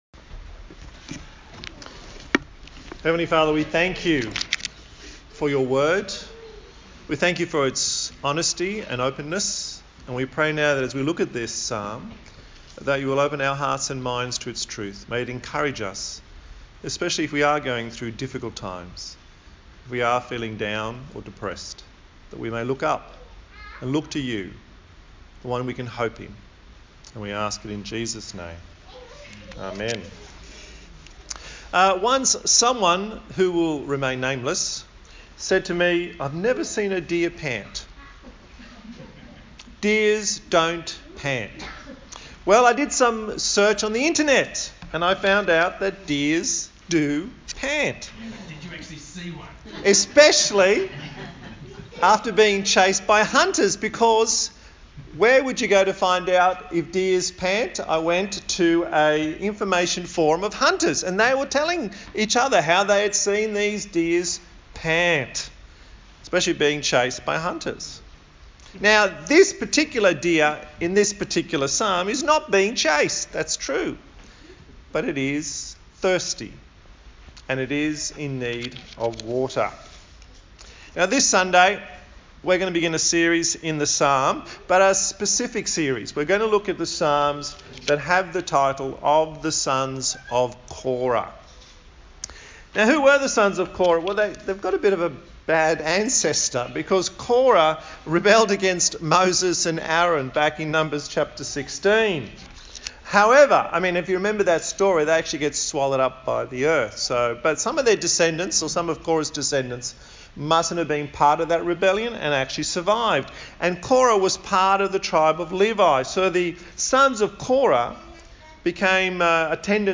Service Type: Sunday Morning A sermon in the series on the book of Psalms